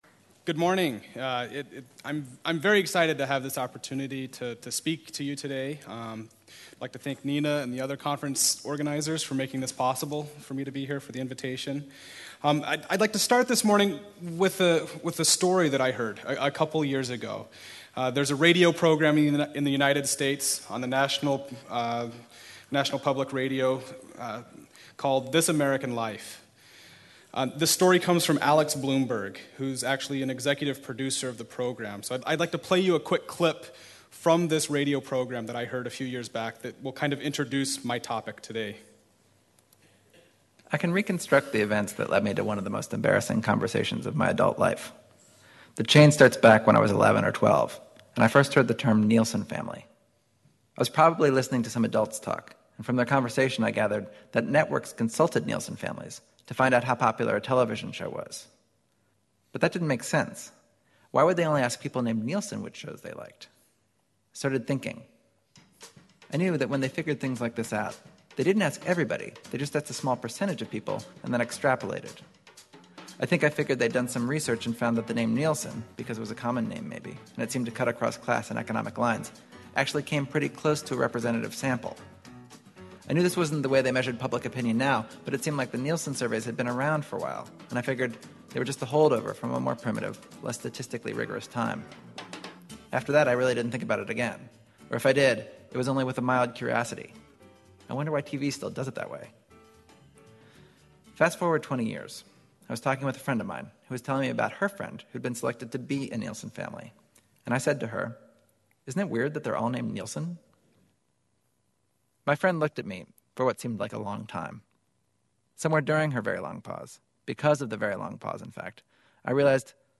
Given February 2010 in Oslo, Norway